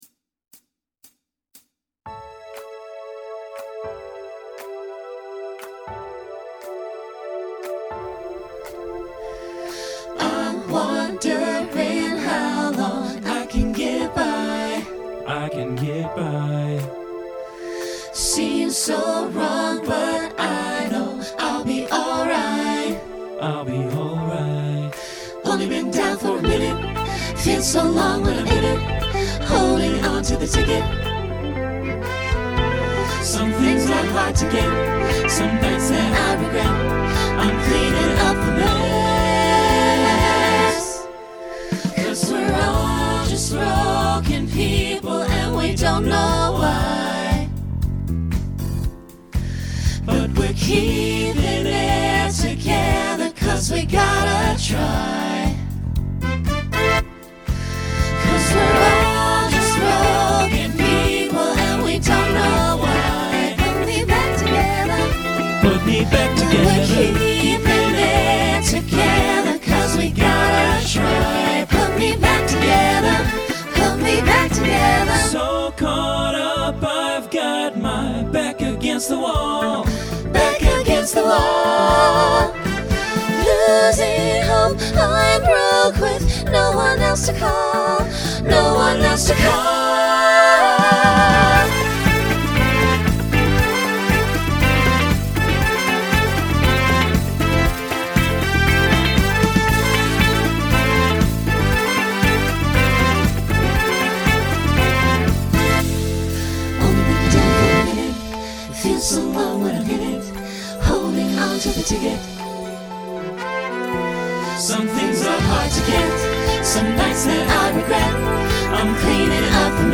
Pop/Dance
Mid-tempo Voicing SATB